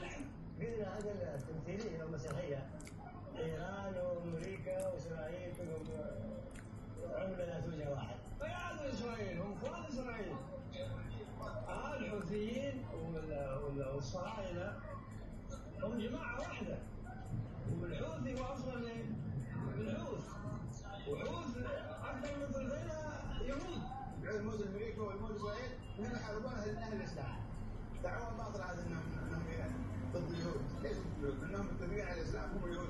מתוך כתבה בערוץ 12 כיצג התימנים מתיחסים למצב